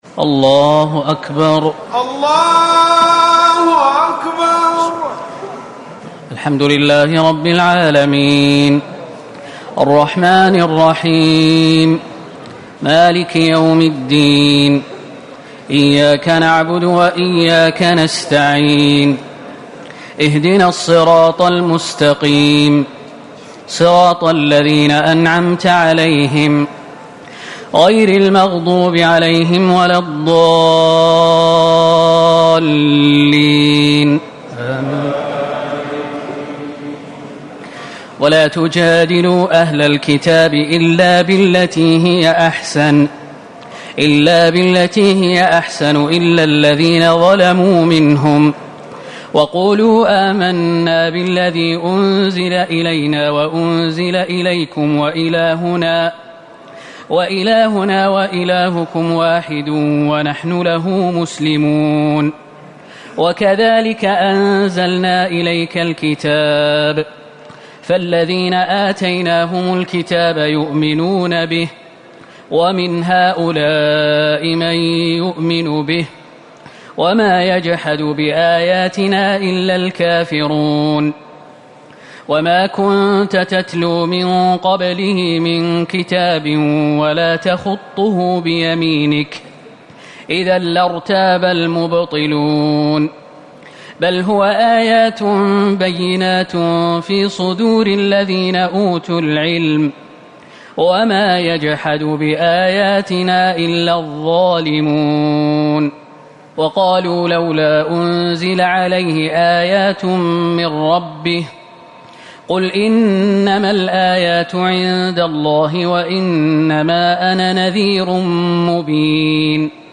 ليلة ٢٠ رمضان ١٤٤٠ من سورة العنبكوت ٤٦ - لقمان ٢١ > تراويح الحرم النبوي عام 1440 🕌 > التراويح - تلاوات الحرمين